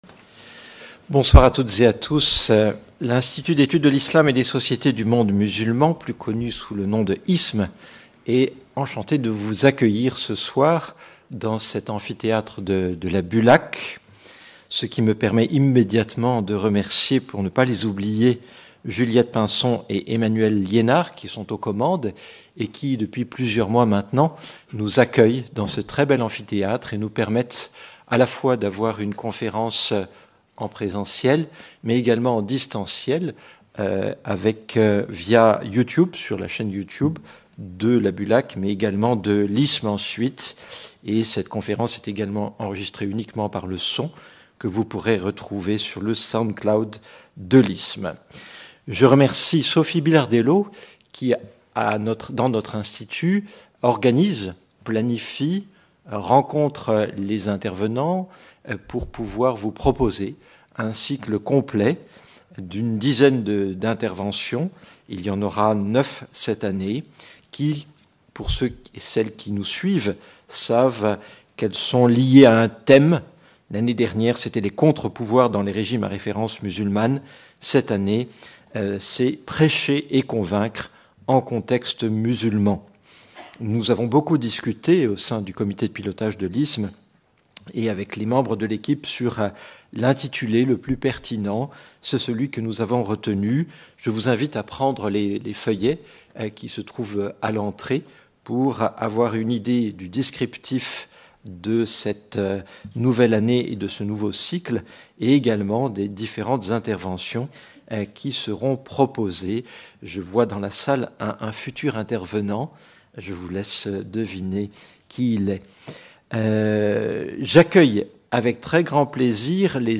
Conférences publiques de l’Institut d’études de l’Islam et des sociétés du monde musulman (IISMM) en partenariat avec la Bibliothèque universitaire des langues et civilisations (BULAC)